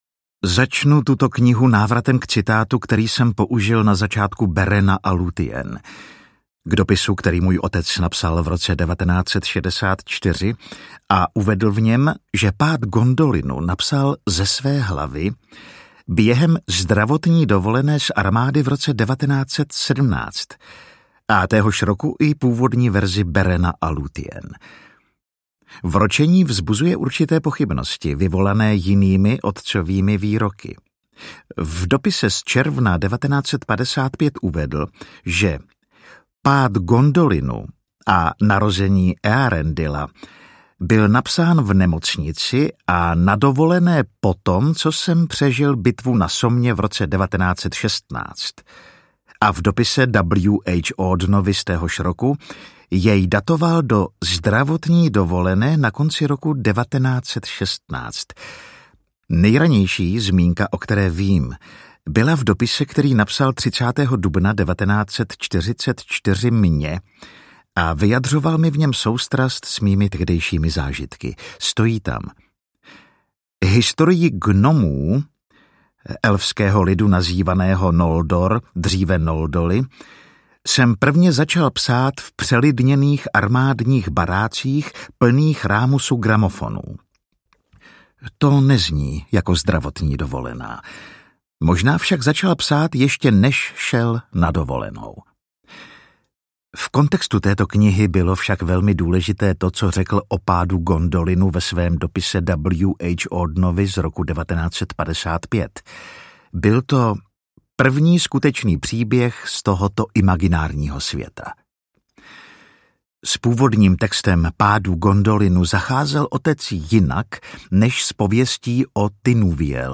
Pád Gondolinu audiokniha
Ukázka z knihy